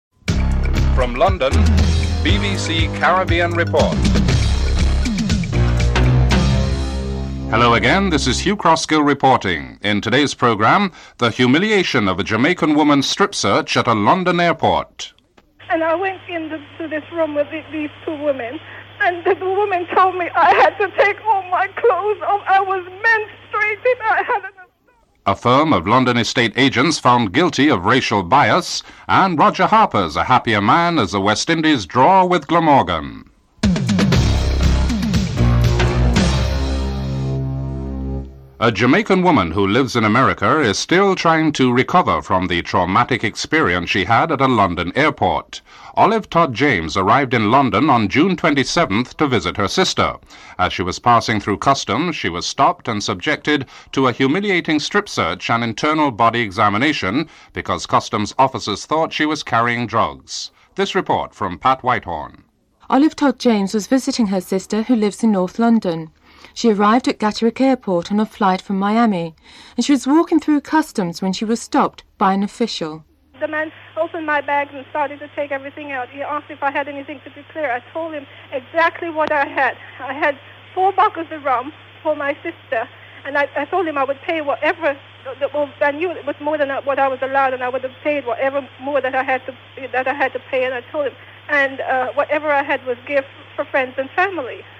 The program ends with highlights of the test match between the West Indies and Glamorgan at Swansea.
7. Sports News.